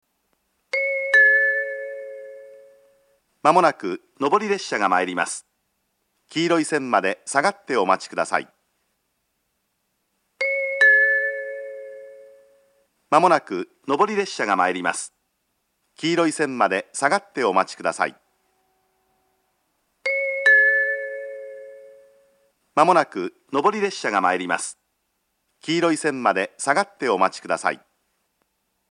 接近放送は秋田支社の新幹線並行区間標準のものが使用されています。
いつ頃かは不明ですが放送装置が更新され、接近放送が変わっています。
２番線接近放送